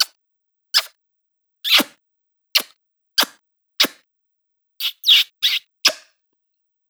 Six Short, Seventh Long and Smoochy